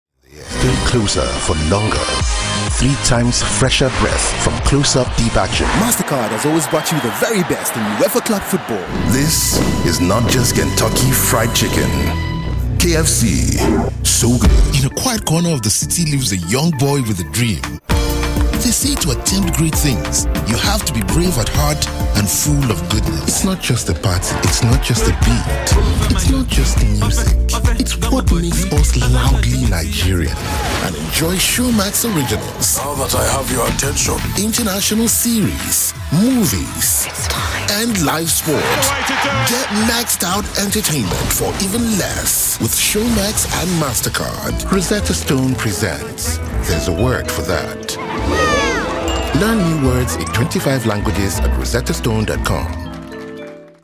English (African)
Conversational
Friendly
Warm